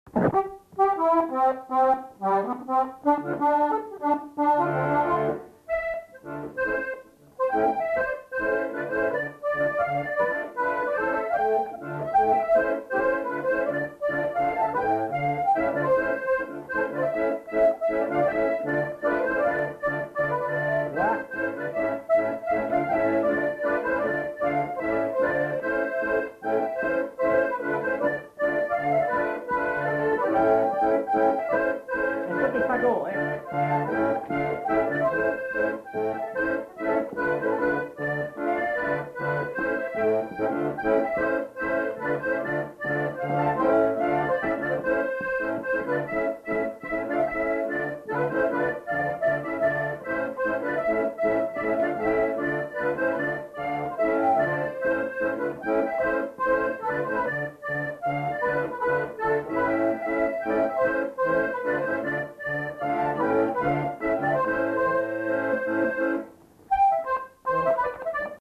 interprété à l'accordéon diatonique
enquêtes sonores